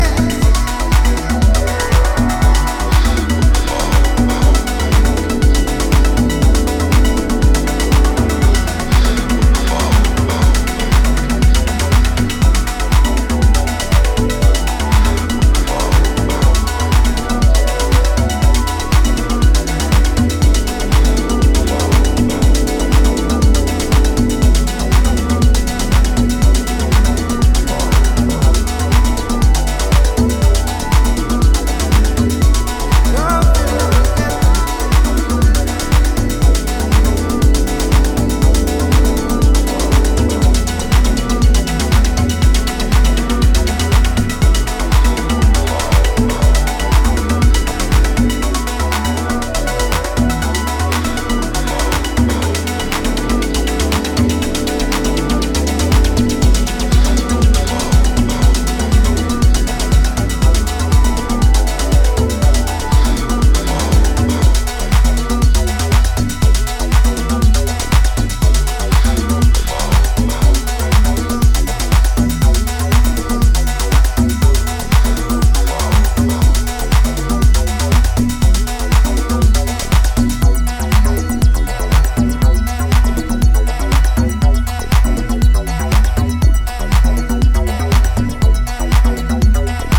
’Teesside Techno’ version